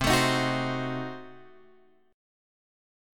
C Diminished 7th